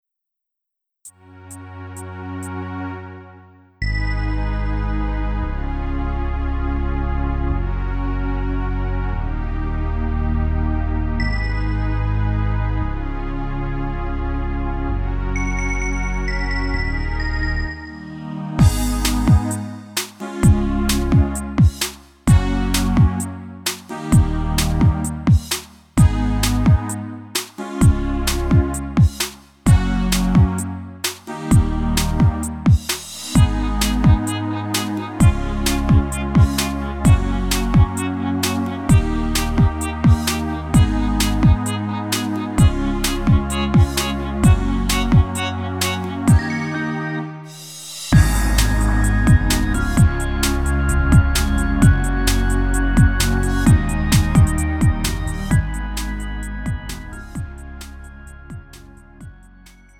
음정 원키
장르 pop 구분 Lite MR